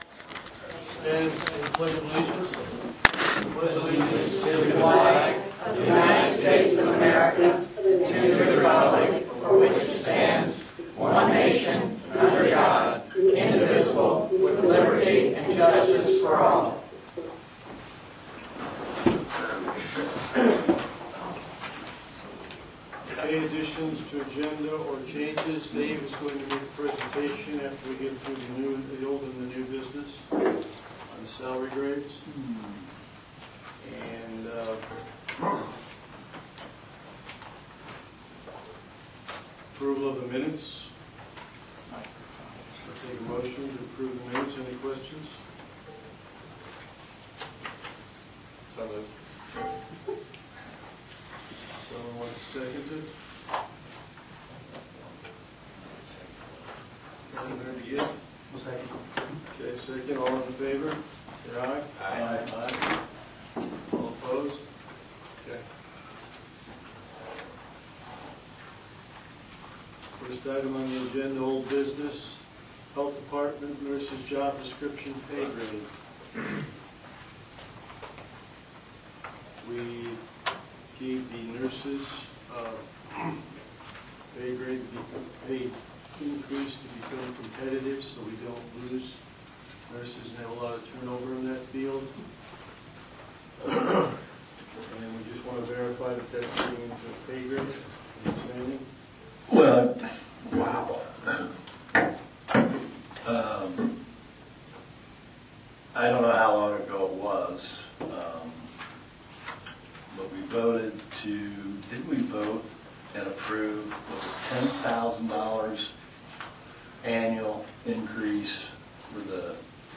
County Council Meeting Notes of Aug 21, 2023